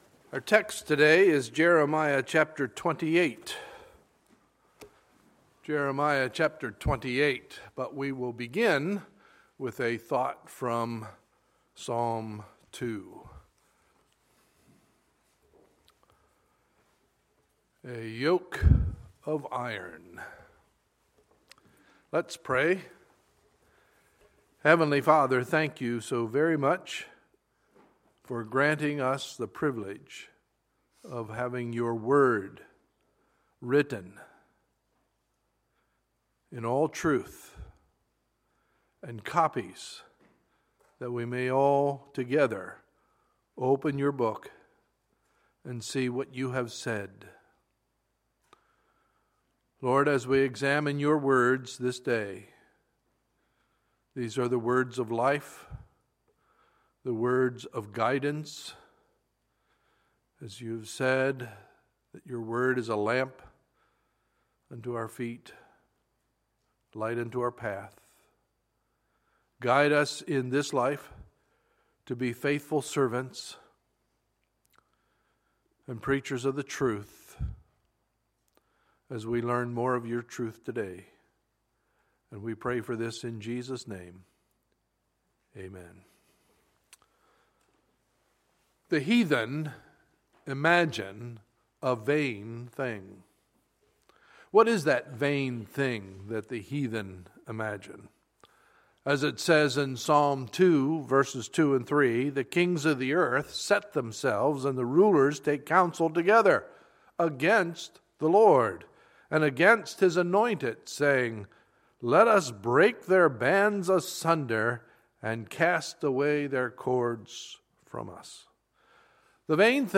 Sunday, September 20, 2015 – Sunday Morning Service